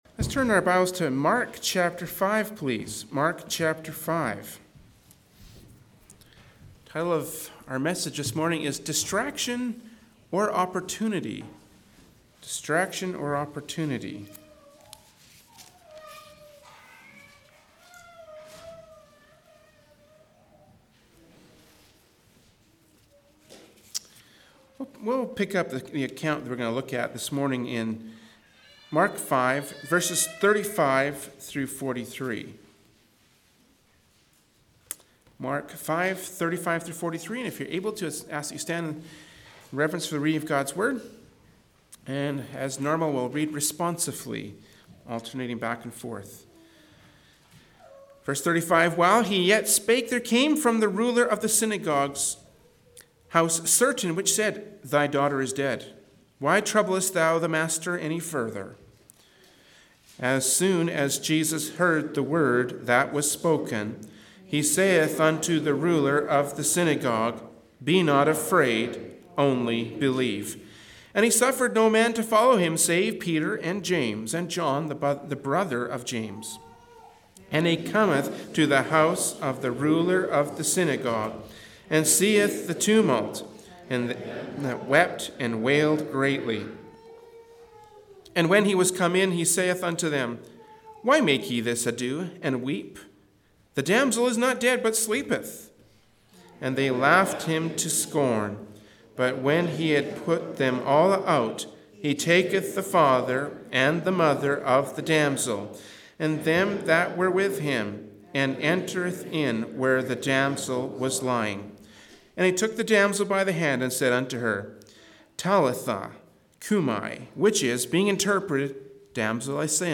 Genre: Preaching.